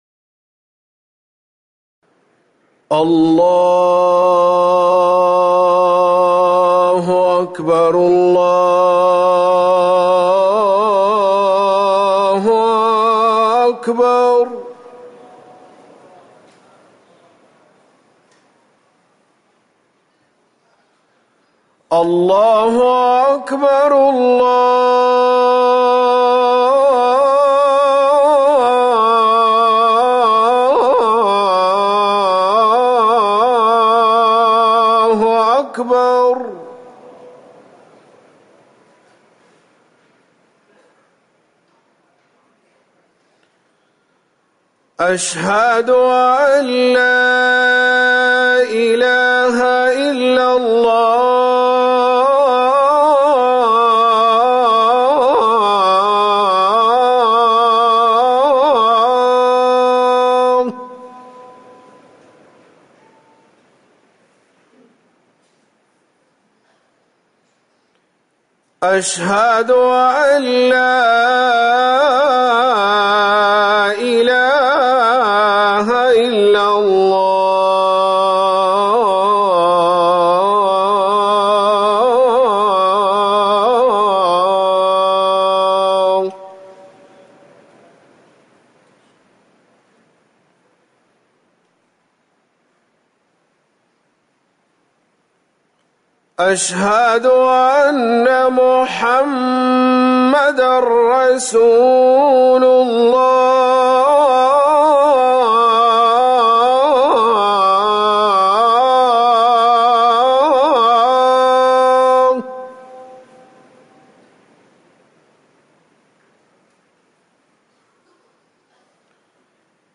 أذان العشاء
تاريخ النشر ١٨ محرم ١٤٤١ هـ المكان: المسجد النبوي الشيخ